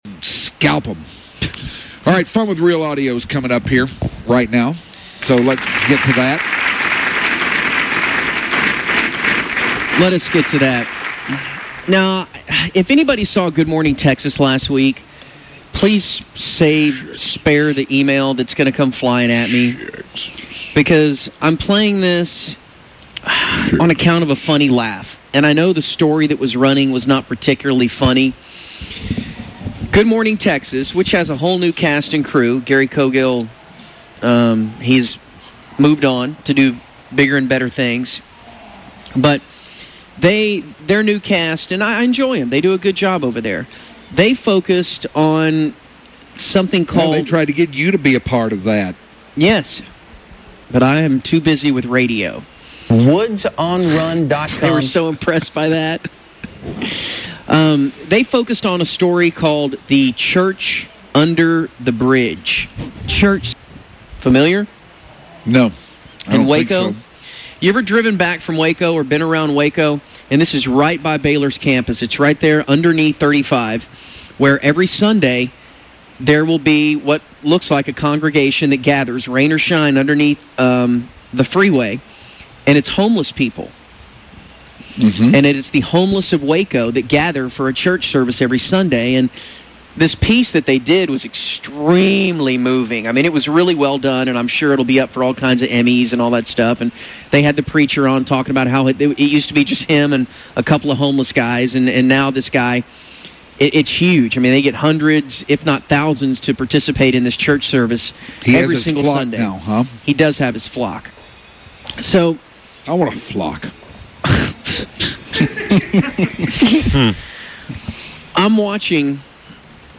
He had some audio to play from a homeless laugh, however, the evil audio equipment ghosts were against him and it made me laugh more than any other part.